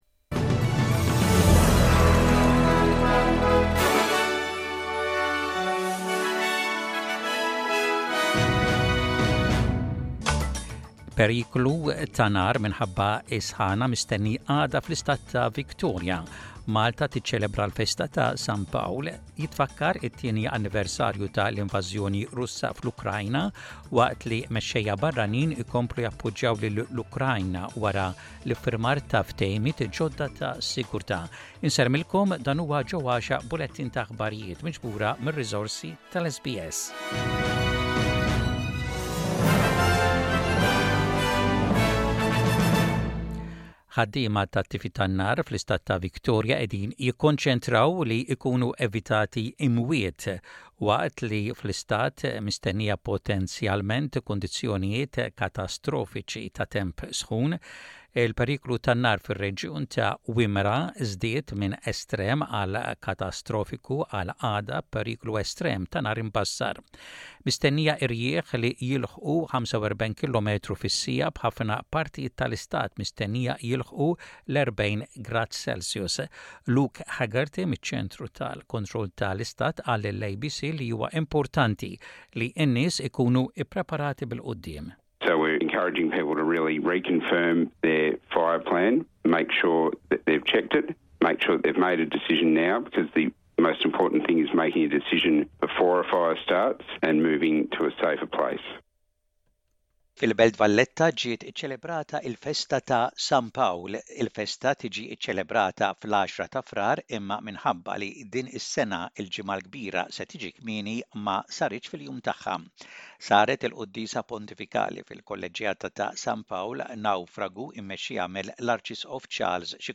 SBS Radio | Maltese News: 27.02.2024